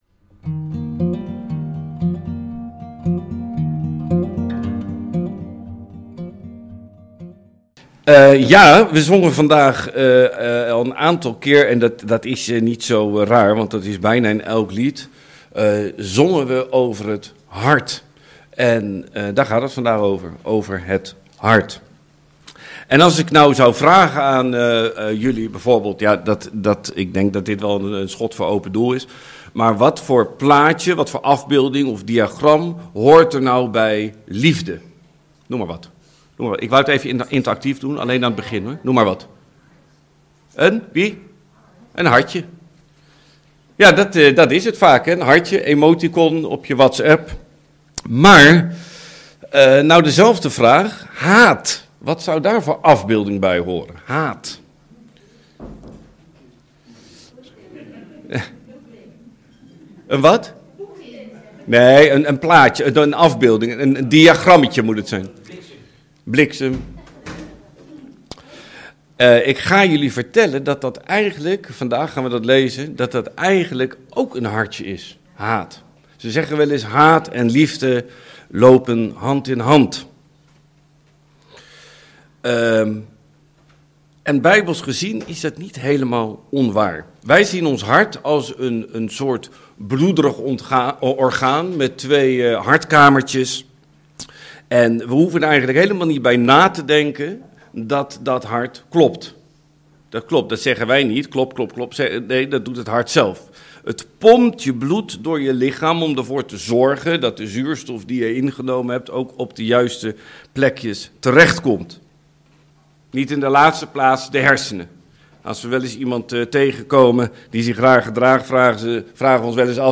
Preken